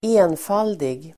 Uttal: [²'e:nfal:dig]